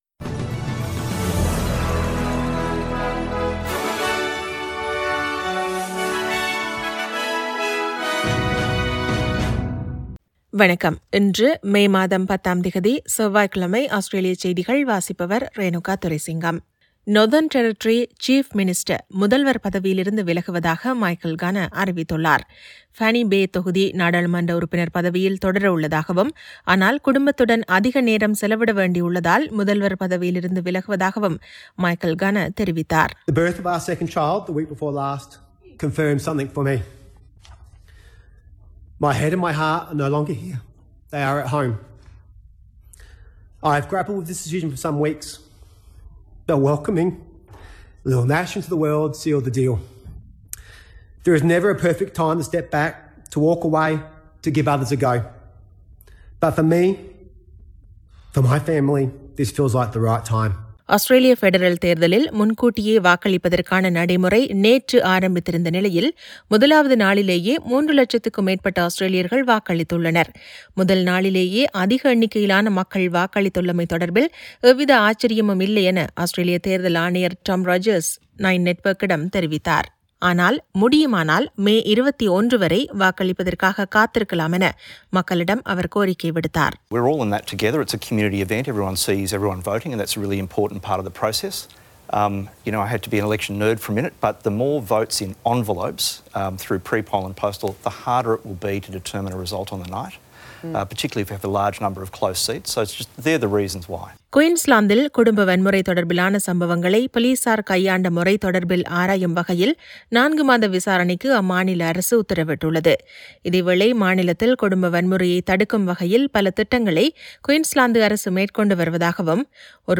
Australian news bulletin for Tuesday 10 May 2022.